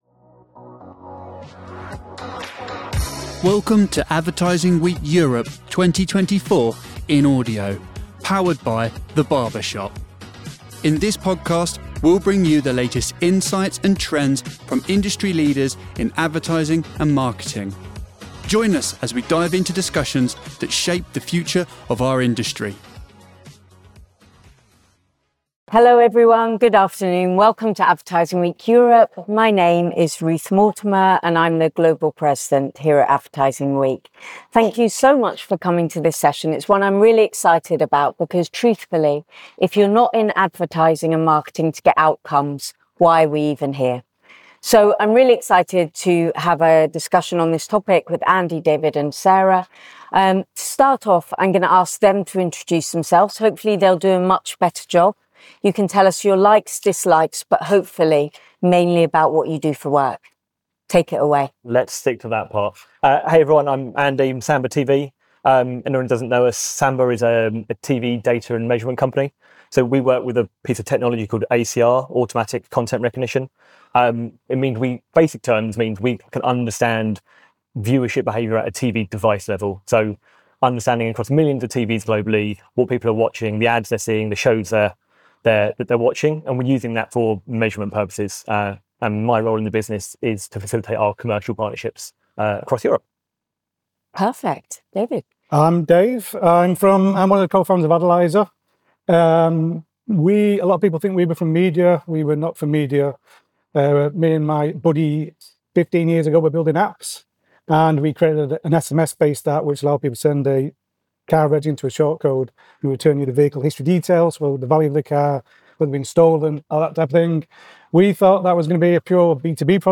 -------------------- Thank you for tuning in to today's episode of the Insights Unveiled: Advertising Week Europe Podcast!